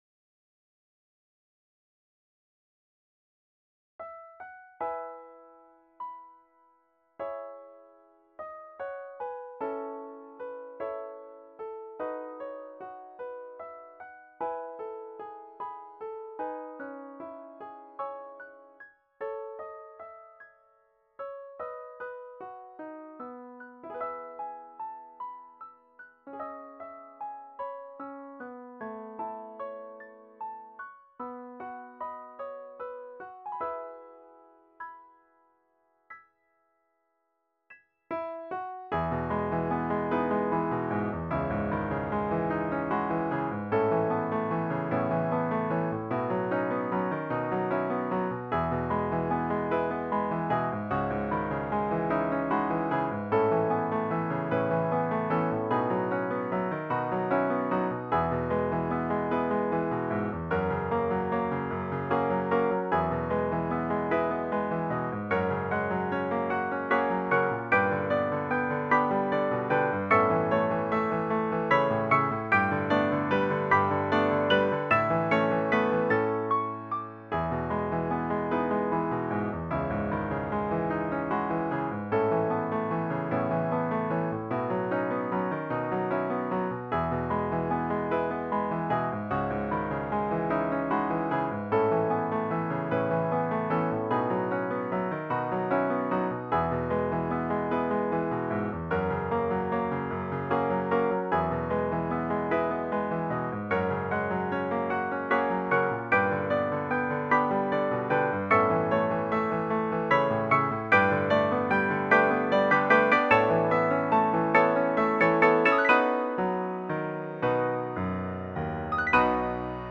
obra para piano a cuatro manos